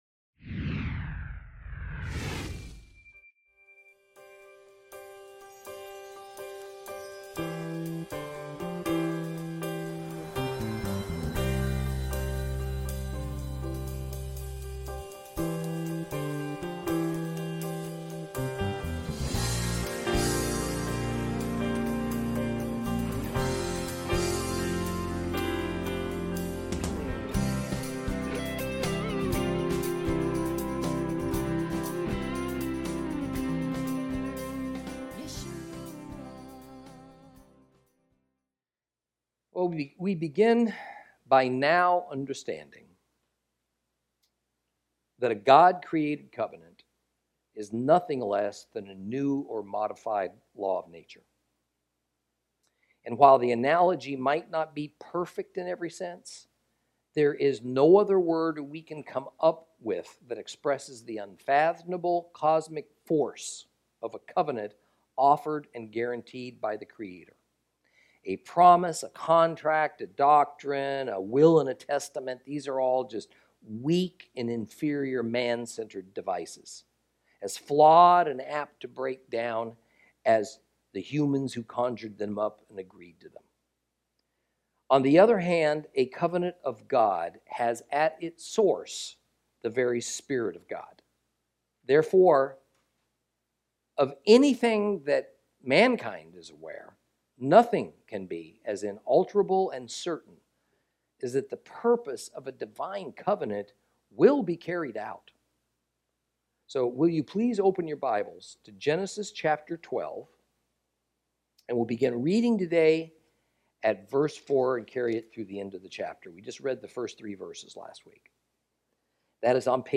Lesson 12 Ch12 Ch13 - Torah Class